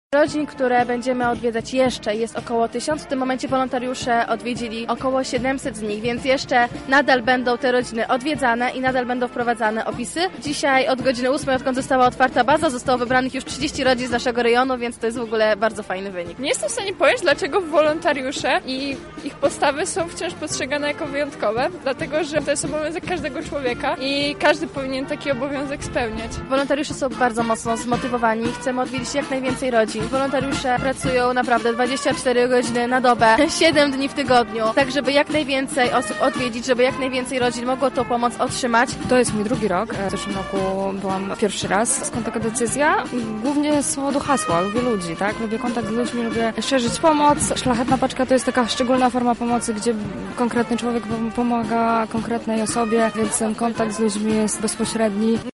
Nasza reporterka rozmawiała z uczestnikami pochodu: